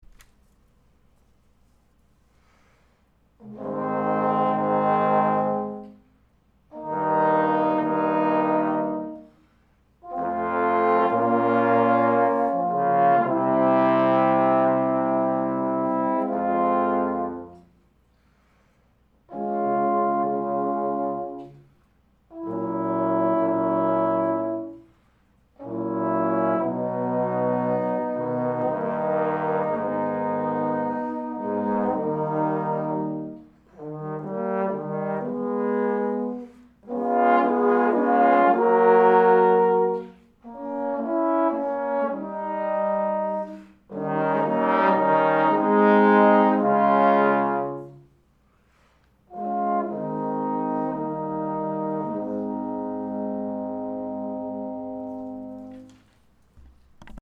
First, print out this PDF – it’s the score to the third movement of the Three Equali trombone quartet by Beethoven…
2. Second part changed to a smaller mouthpiece